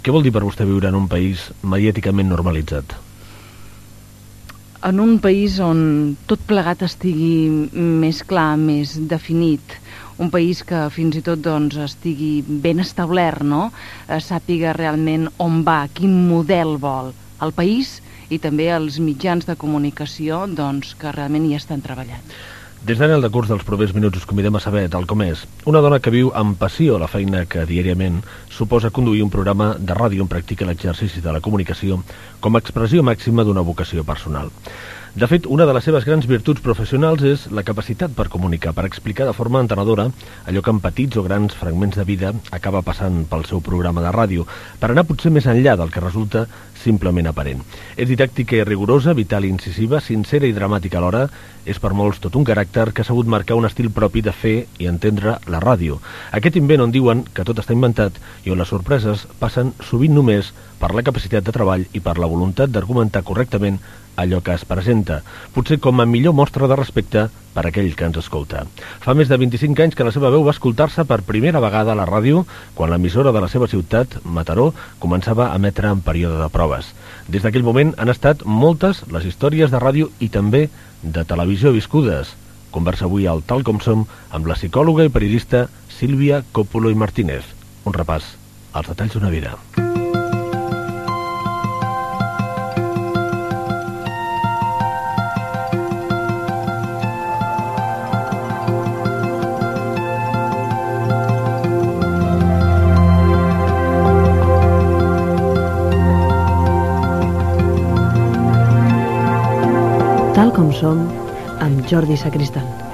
Indicatiu del programa
Divulgació
FM